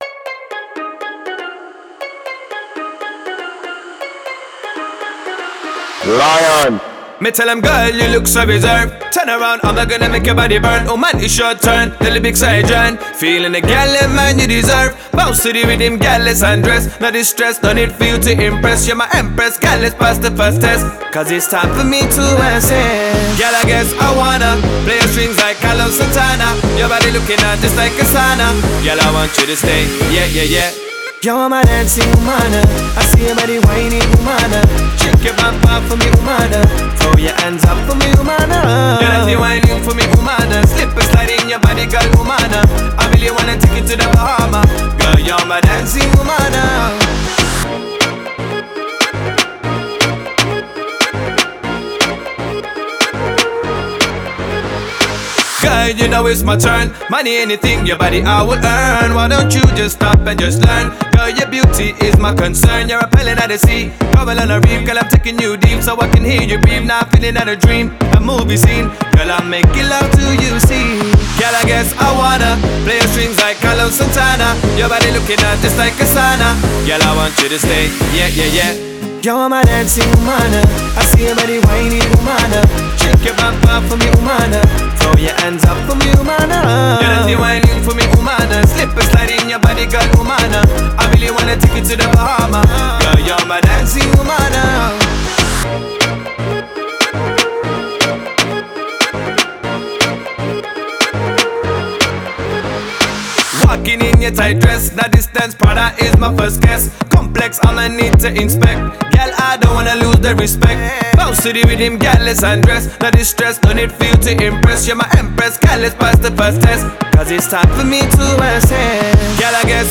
punjabi song
sweet voice